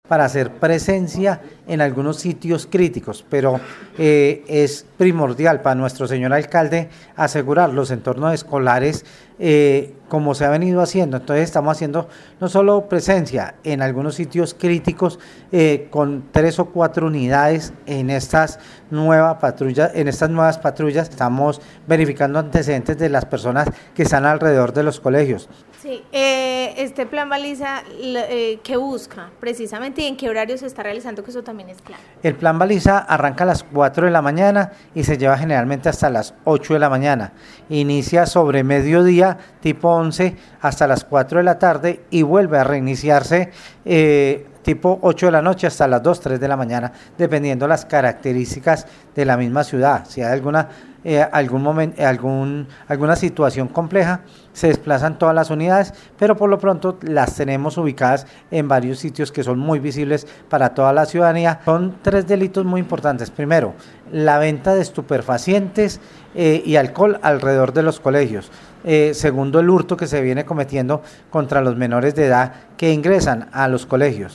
Secretario de Gobierno de Armenia, Carlos Arturo Ramírez